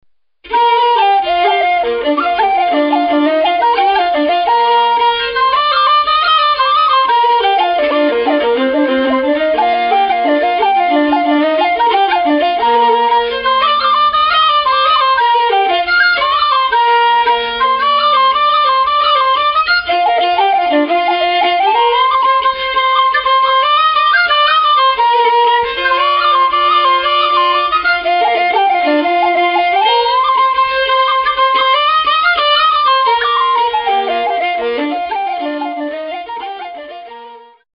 The music on this CD is played in the pitches of B and Bb.
Thus the sound is mellow and smooth.